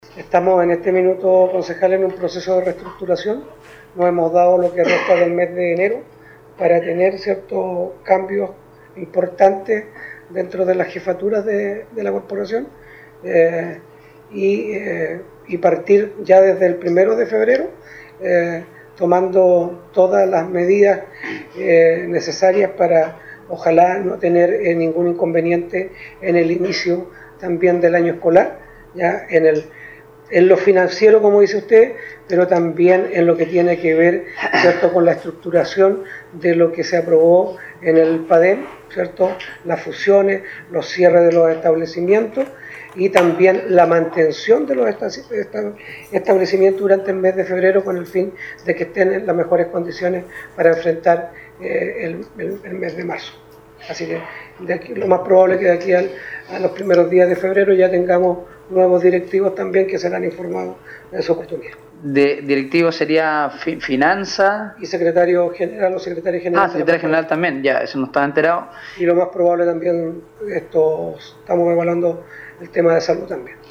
En sesión de Concejo municipal este lunes, el alcalde Carlos Gómez señaló que actualmente están en revisión y evaluación de todos los cargos directivos de ambas áreas, educación y salud.